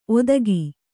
♪ odagi